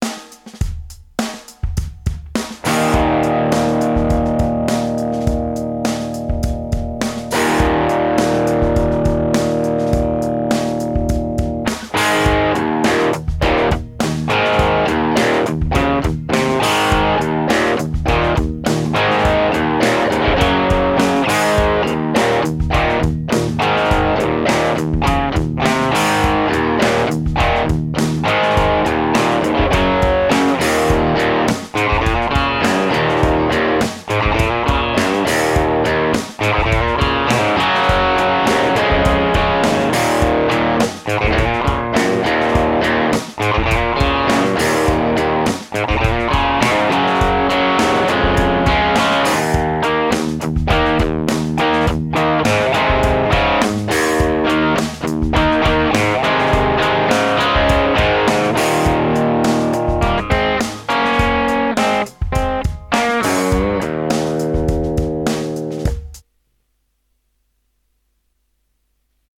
kleinen Schnippsel aufgenommen, bei dem man den "dunklen Twang" den diese PUs produzieren - wie ich finde - ganz gut hört.